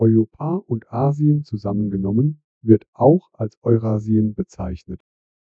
sample03-griffin-lim.wav